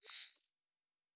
Sci-Fi Sounds / Weapons
Additional Weapon Sounds 1_4.wav